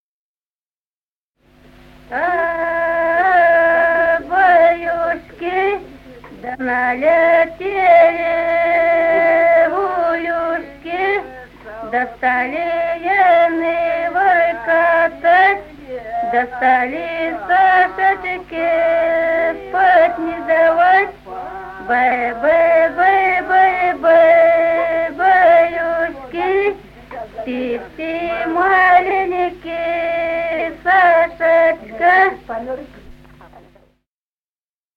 Народные песни Стародубского района «А-а, баюшки», колыбельная.
с. Остроглядово.